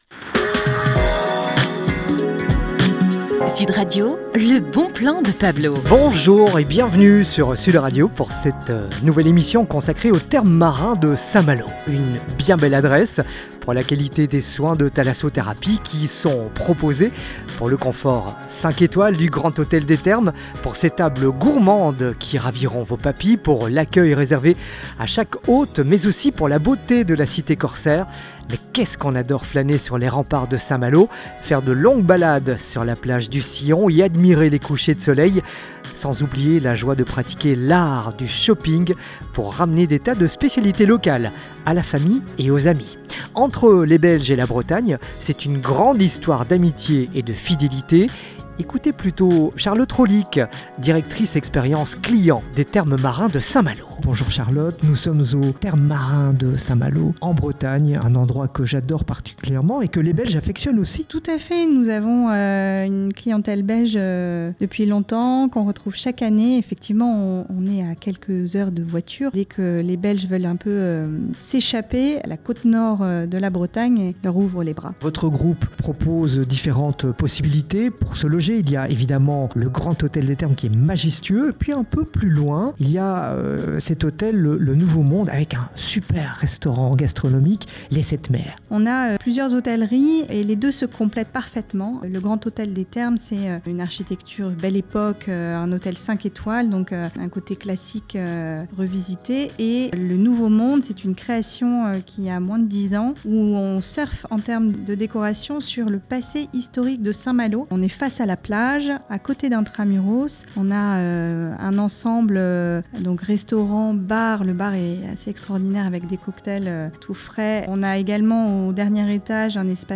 Interview Sud Radio